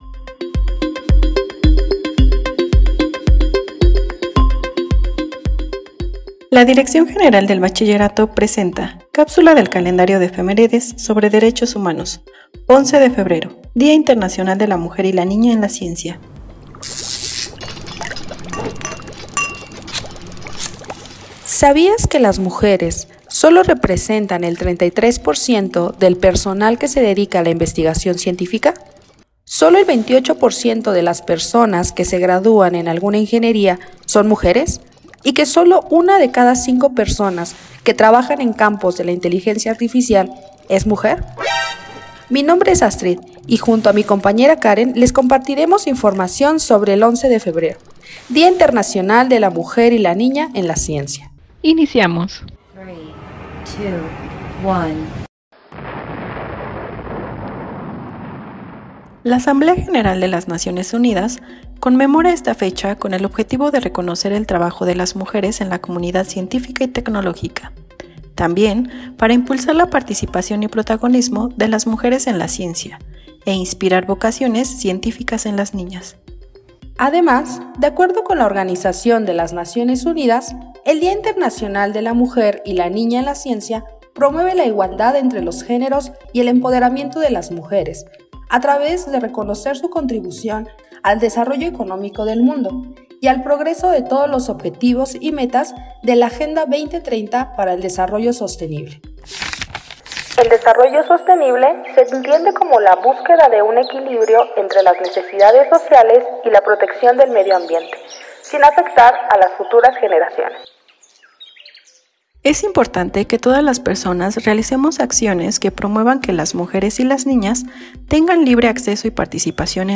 Cápsula de audio informativa